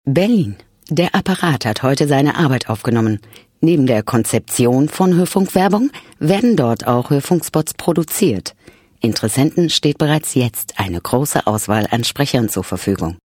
• Rundfunk-/Werbespots